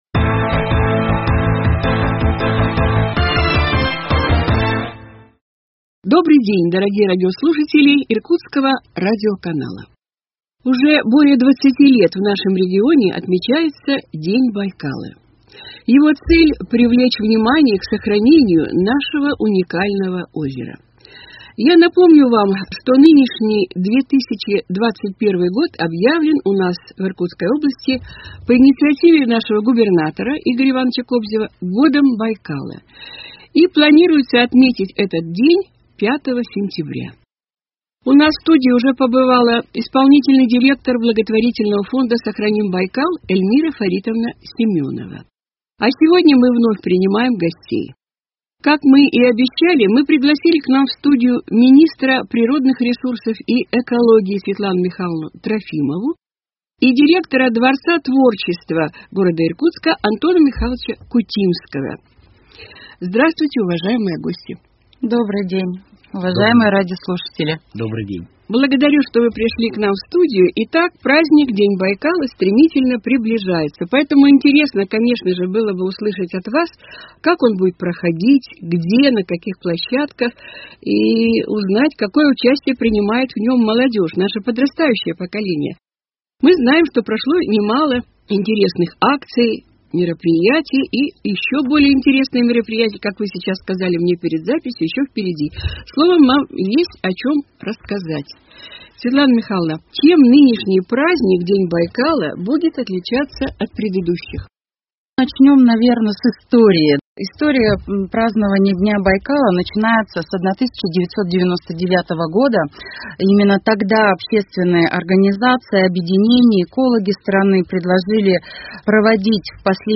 Актуальное интервью: День Байкала 02.09.2021